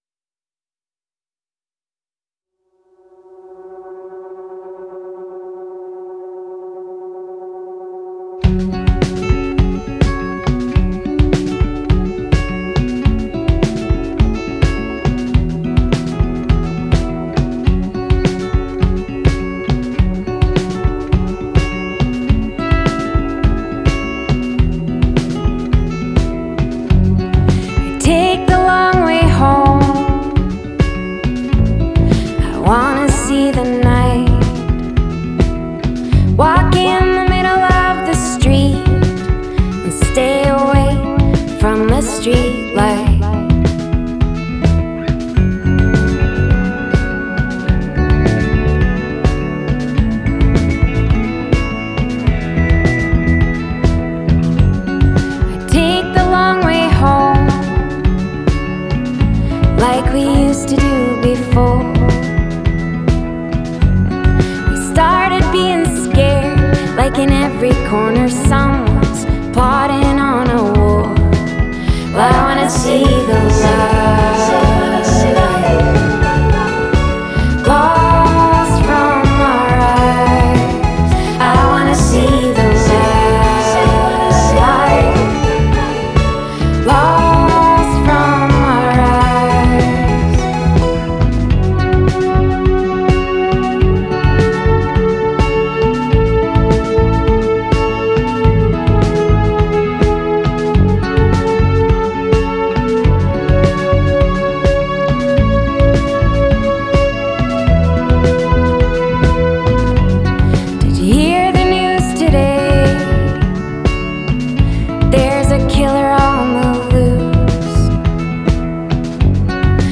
a world class bassist, singer, and fantastic entertainer.